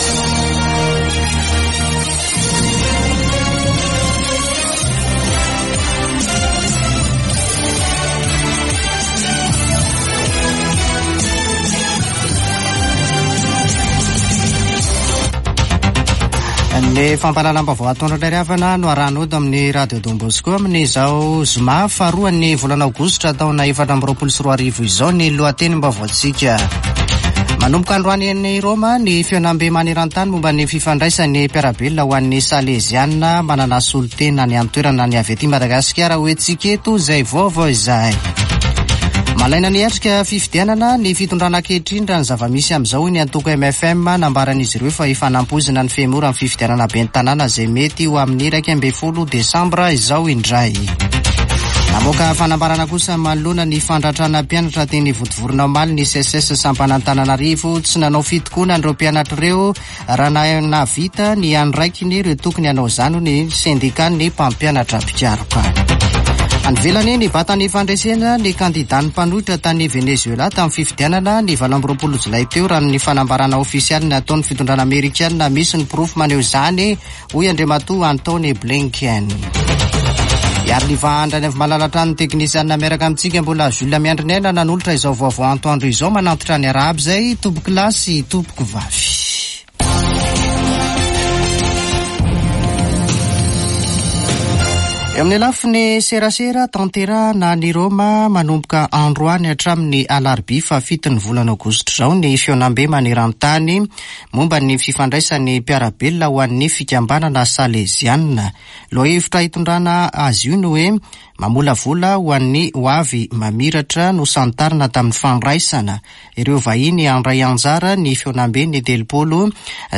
[Vaovao antoandro] Zoma 2 aogositra 2024